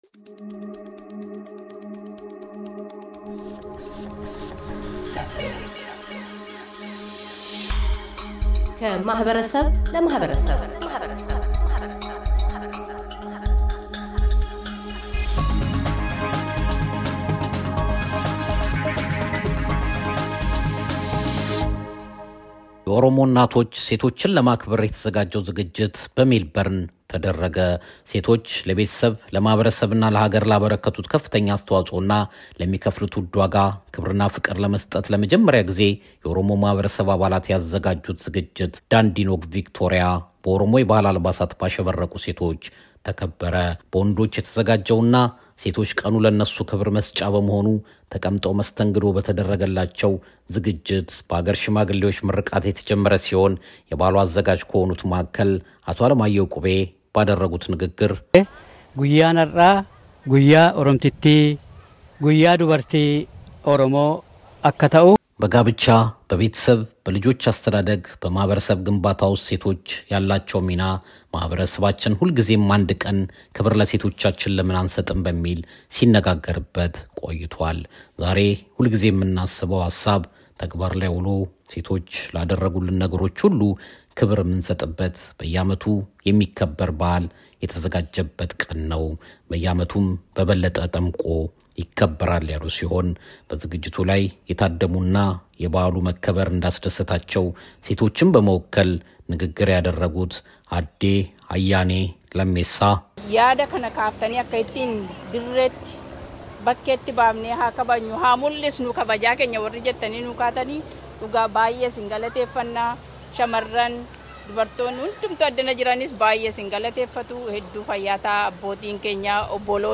የኦሮሞ እናቶች/ሴቶች የምስጋና ቀን እሑድ ማርች 3 / የካቲት 24 ሜልበርን ከተማ ውስጥ ተካሂዶ ውሏል። የዝግጅቱ ስተባባሪዎችና ታዳሚዎች አተያያቸውን ያጋራሉ።